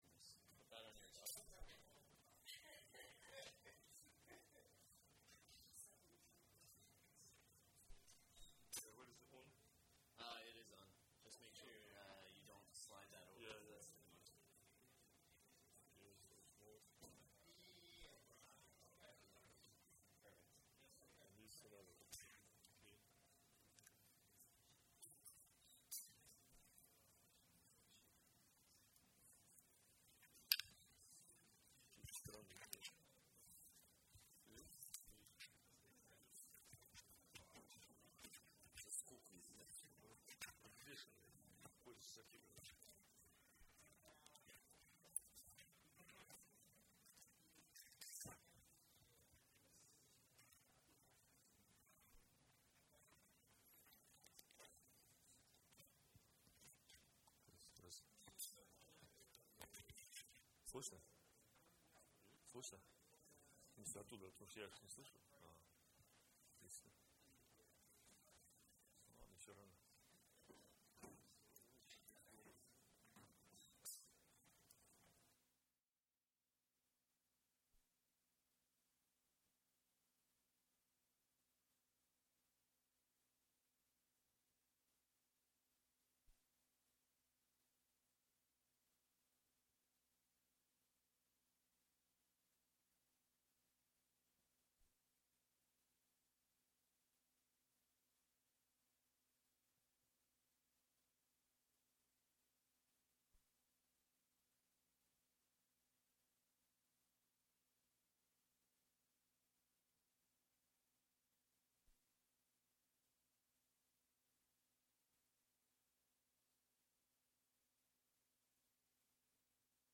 Event type Lecture